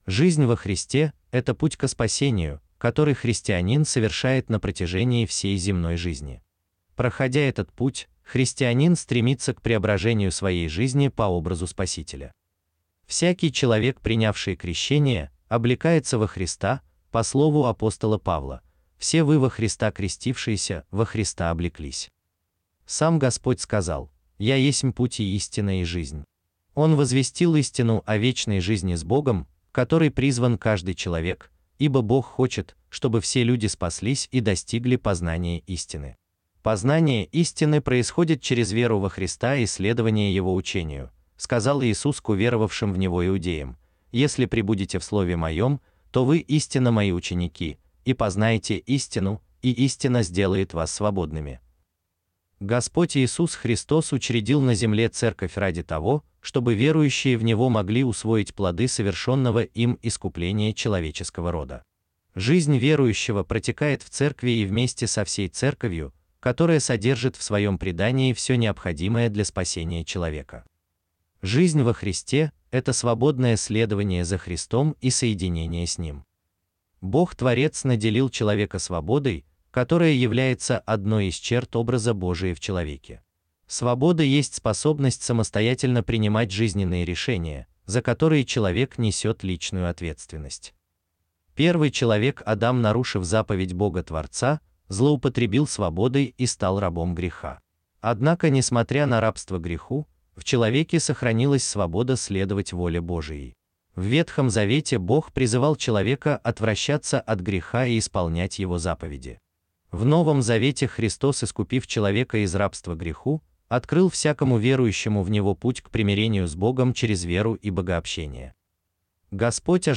Аудиокнига Основы православного нравственного учения | Библиотека аудиокниг